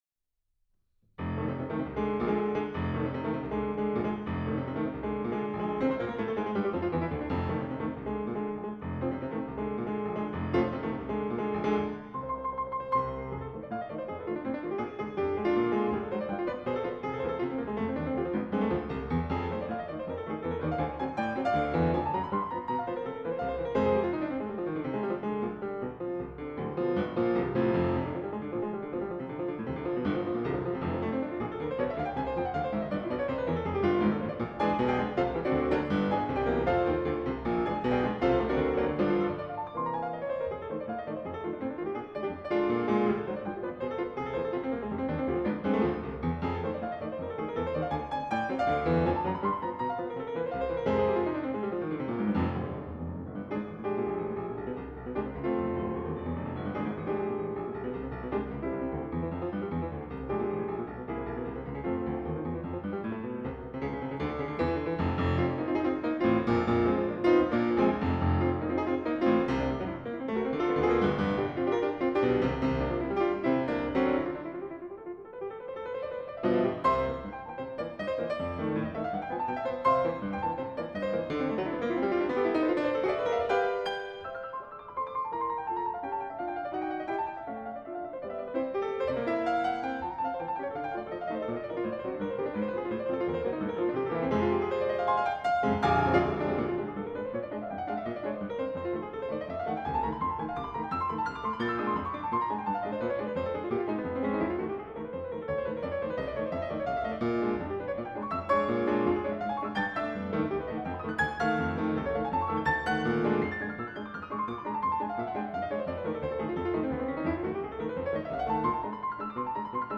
Piano stereo pair (unprocessed)